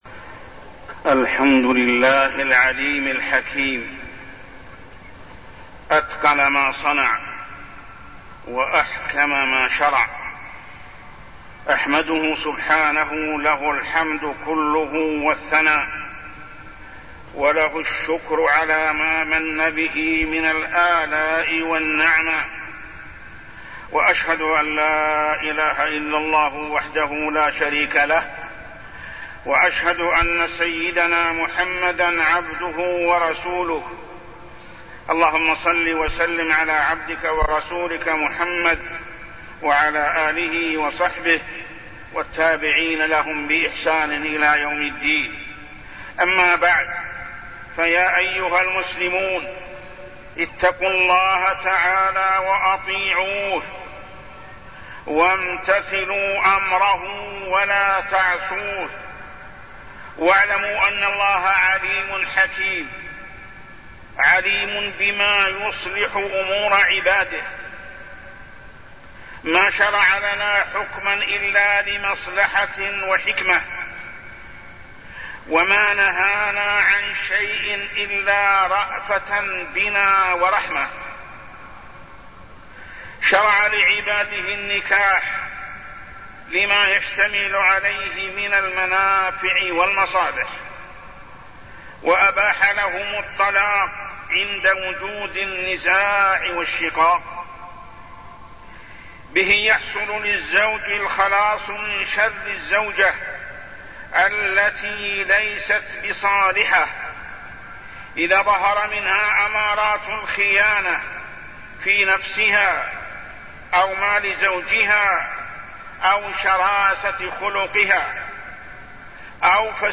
تاريخ النشر ٢١ شوال ١٤٢٠ هـ المكان: المسجد الحرام الشيخ: محمد بن عبد الله السبيل محمد بن عبد الله السبيل الطلاق